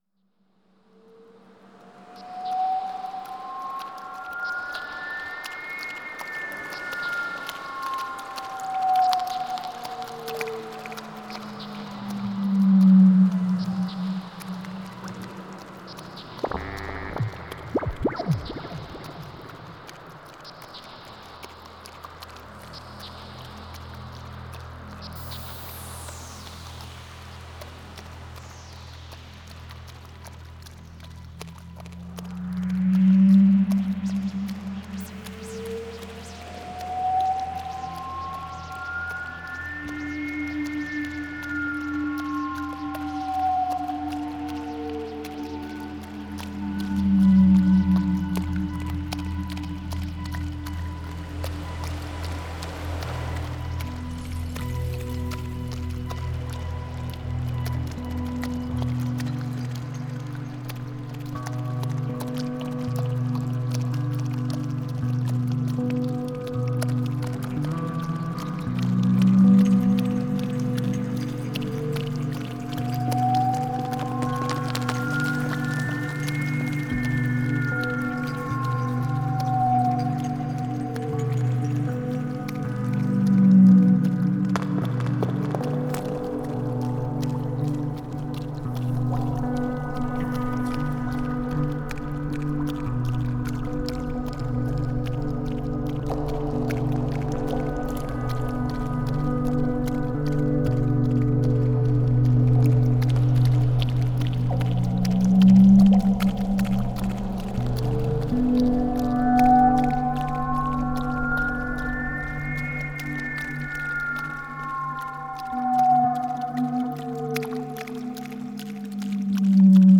Genre: Dub, Downtempo, Ambient.